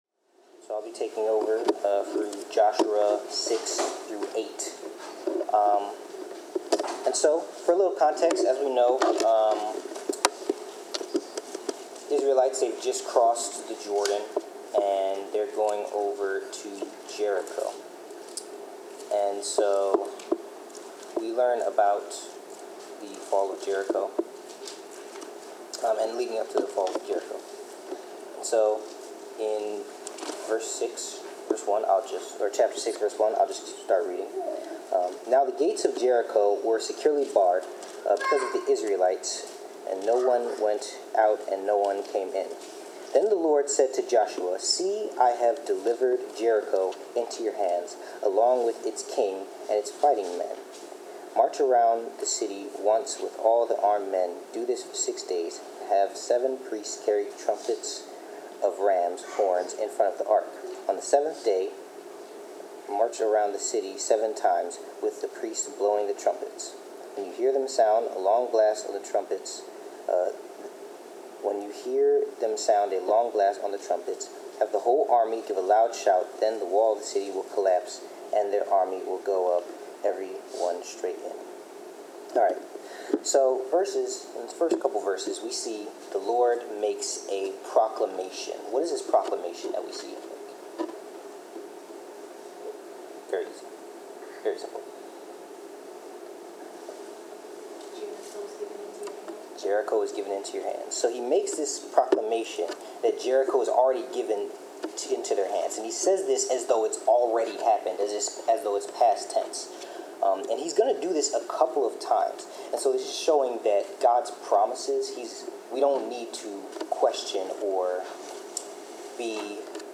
Due to unforeseen technical difficulties, the recording was cut short.
Joshua 6-7:1-2 Service Type: Bible Class Due to unforeseen technical difficulties